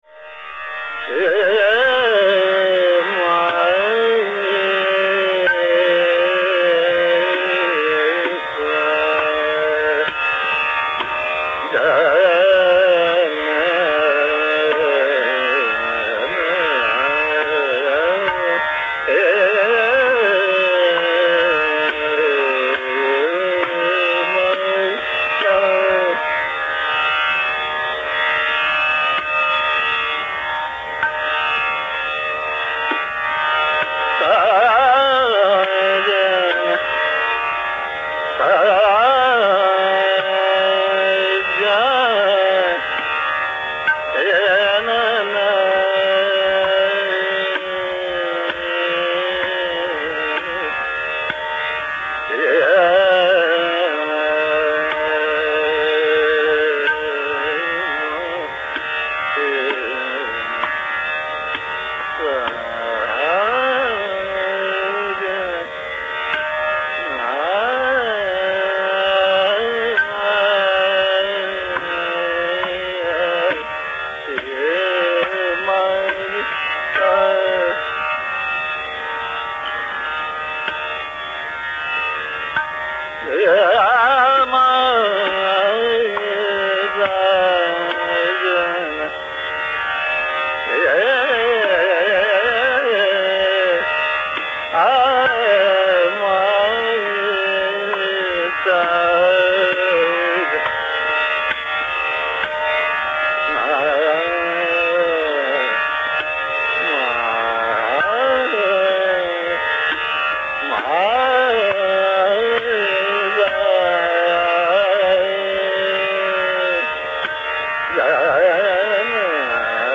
stately performance